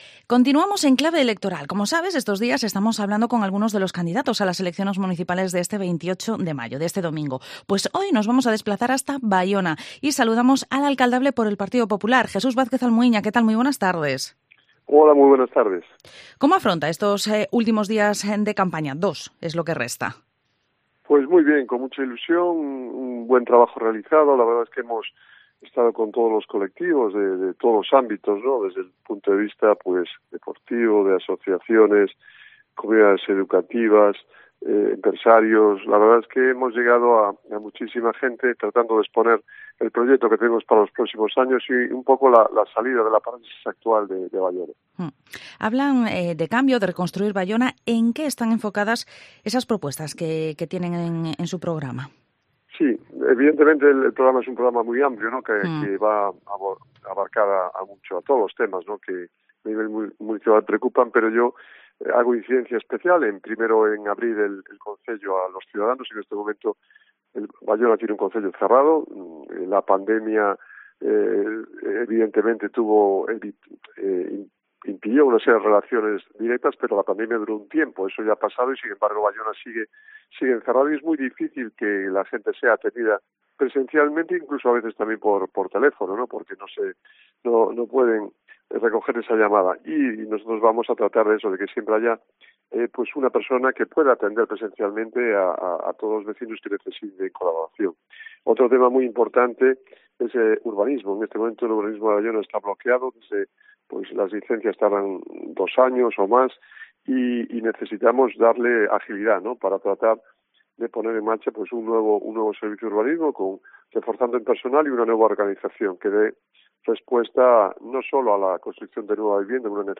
Entrevista al candidato del PP en Baiona, Jesús Vázquez Almuiña